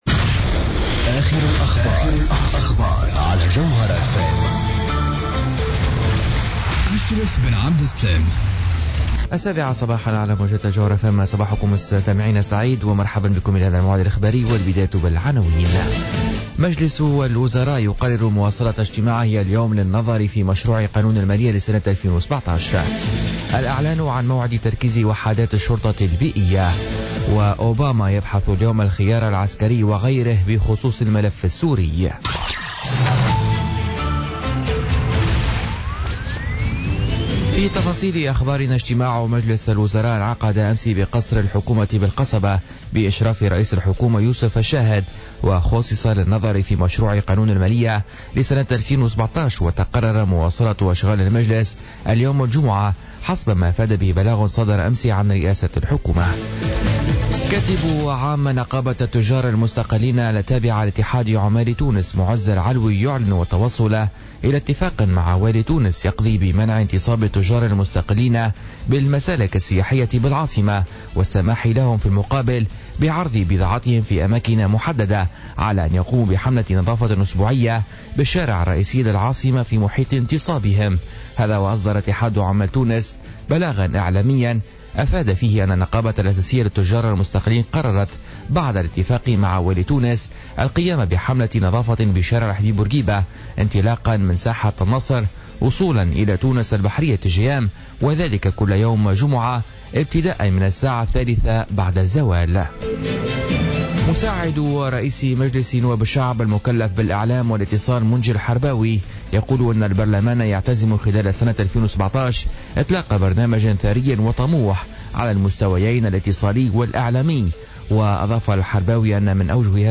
نشرة أخبار السابعة صباحا ليوم الجمعة 14 أكتوبر 2016